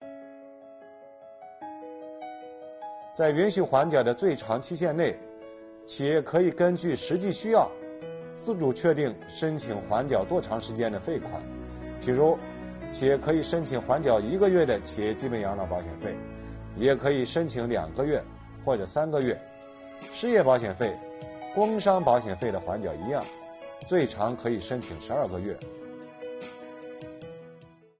近日，国家税务总局推出新一批“税务讲堂”系列课程，为纳税人缴费人集中解读实施新的组合式税费支持政策。本期课程由国家税务总局社会保险费司副司长王发运担任主讲人，对公众关注的特困行业阶段性缓缴企业社保费政策问题进行讲解。